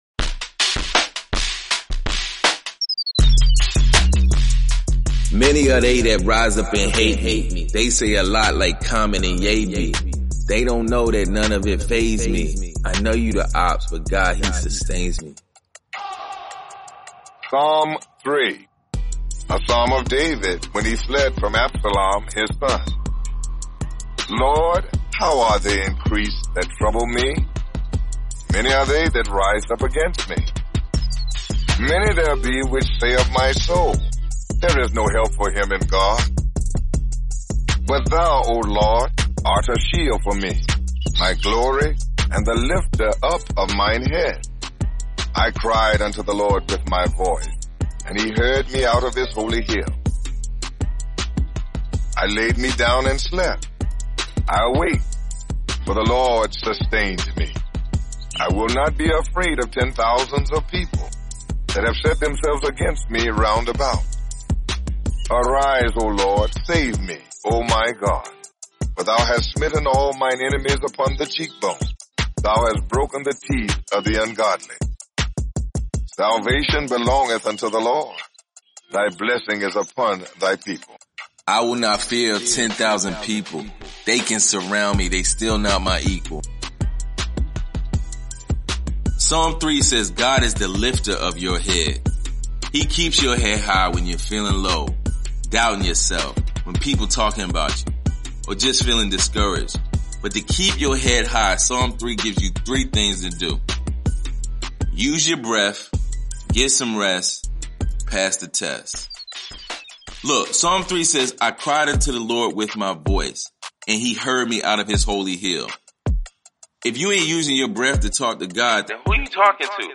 Experience the Psalms in a fresh new way! This narrated devotional provides encouraging insight over original music that injects hope, faith, wisdom, inspiration, and so much more through the Psalms!